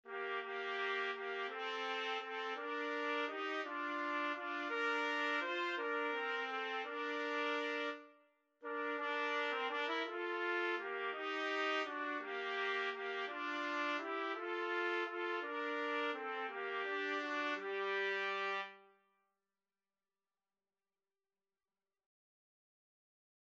One in a bar . = 56
3/4 (View more 3/4 Music)
G minor (Sounding Pitch) A minor (Trumpet in Bb) (View more G minor Music for Trumpet Duet )
Trumpet Duet  (View more Easy Trumpet Duet Music)
Traditional (View more Traditional Trumpet Duet Music)